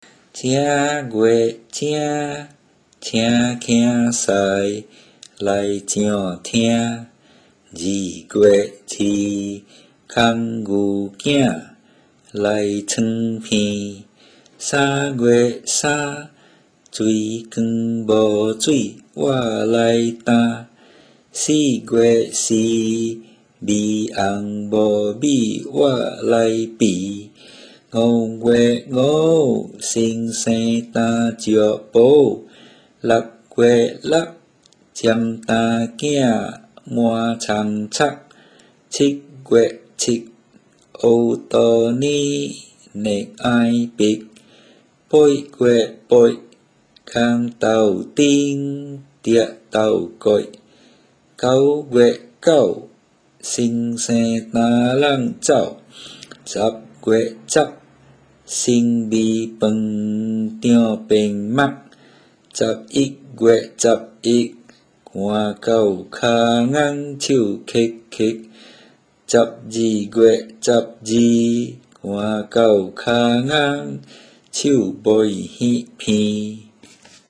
TwelveMonths_Recite.mp3